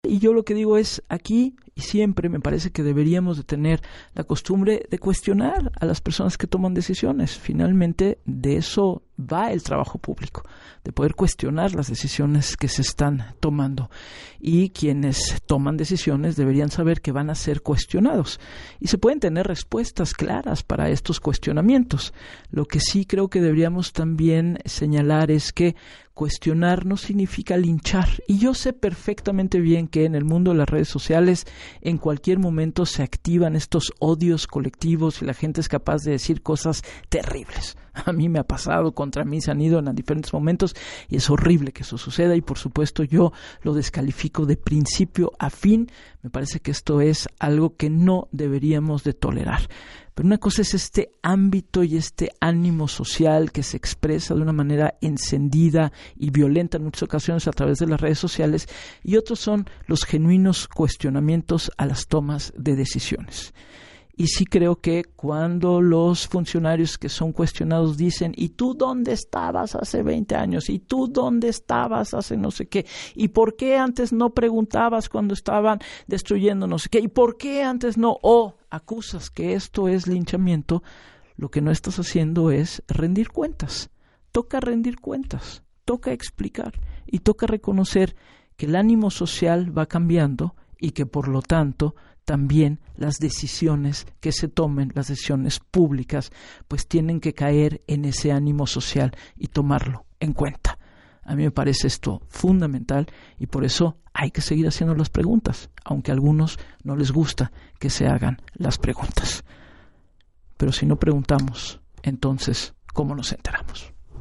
Gabriela Warkentin, periodista